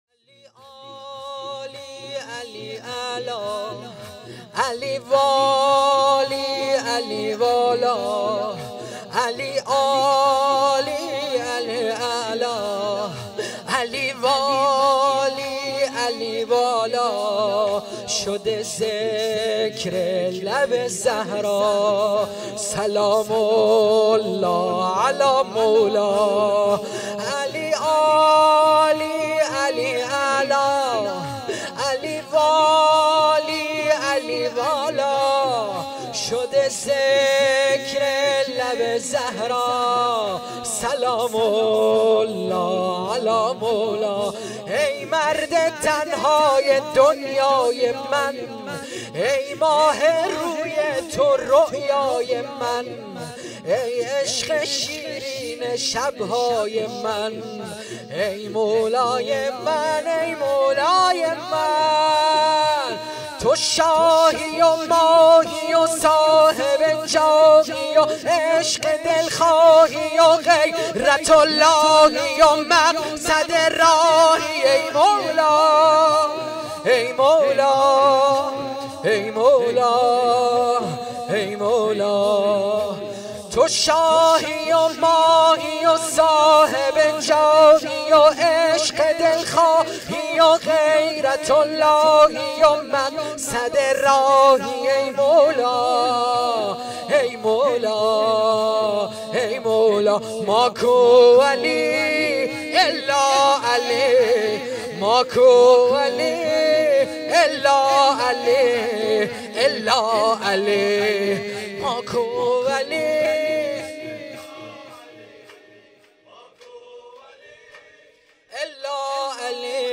زمینه | علي عالي علي اعلا
مداحی زمینه
شب هجدهم ماه رمضان 1442 ه.ق (شب های قدر) | هیأت علی اکبر بحرین